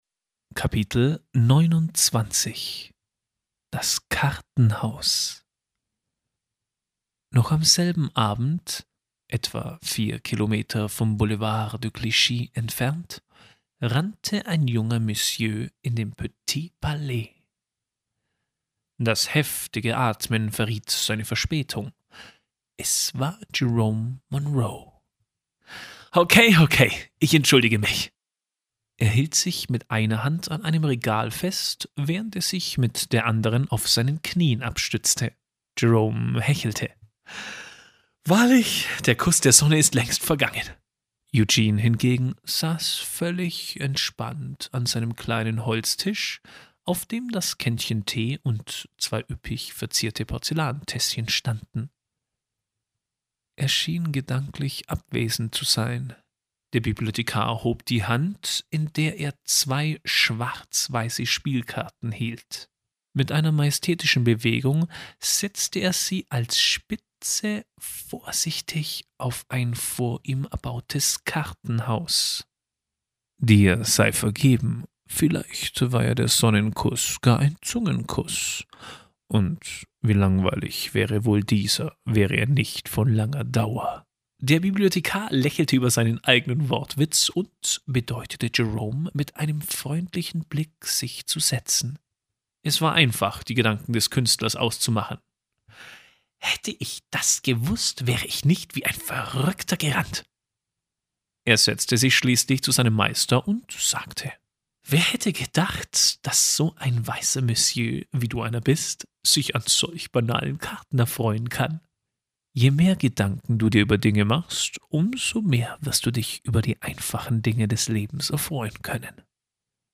Lese- und Medienproben